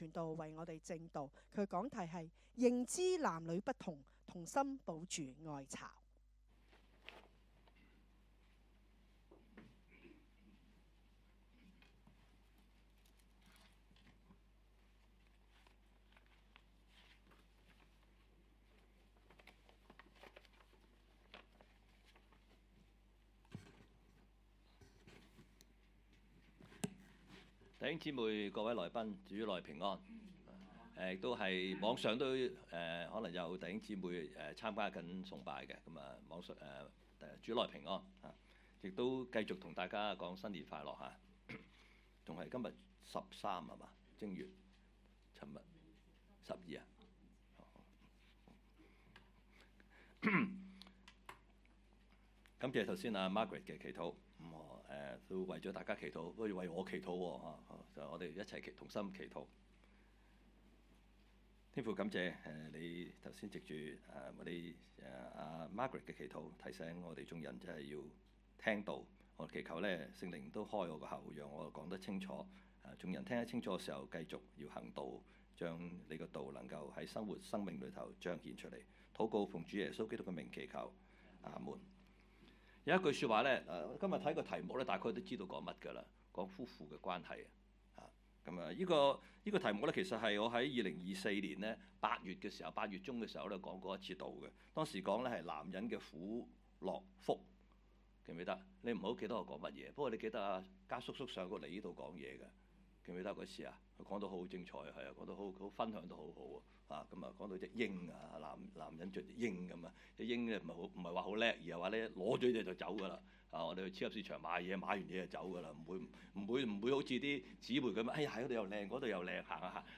講道 ：認知男女不同，同心保住愛巢 讀經 : 弗5：22 – 33